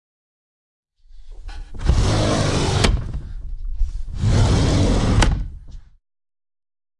衣柜抽屉 " 家用衣柜抽屉滑动金属开合运动 咆哮动物005
这是内部抽奖之一的近距离录音。对于这个录音我使用我的Sennheiser MKH416进入声音设备mp1前置放大器并录制到Tascam DR680。
Tag: 滑动 打开 关闭 衣柜 动物 金属 轰鸣 绘制 弗利